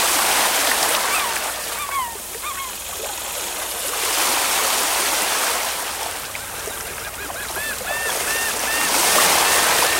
Bruitage – Bord de mer – Le Studio JeeeP Prod
Bruitage haute qualité créé au Studio.
Bord-de-mer.mp3